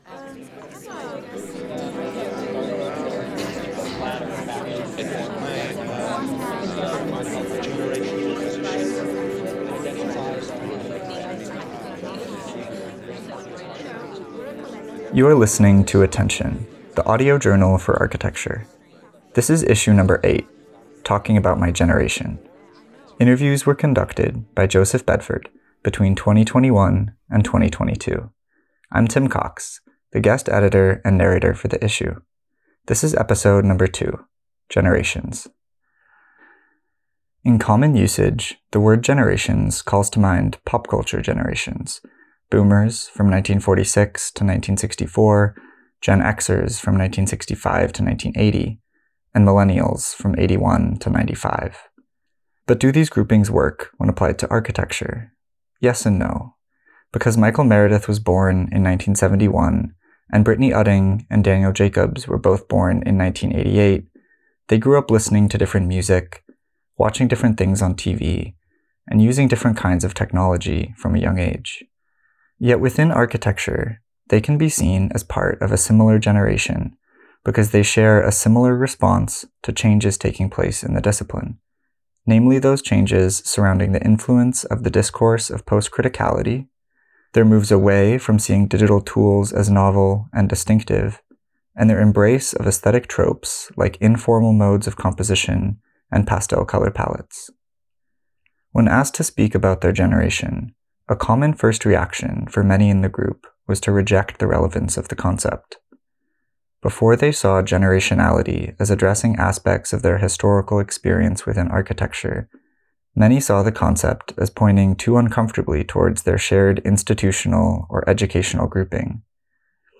In this episode we hear from the group of American academic architects that has been brought together by this issue of attention. The group pushes back against their characterization as a generation, though there are throughlines in the group— namely their responses to the digital project, the crash of 2008, and their individual, pluralized approaches to architecture.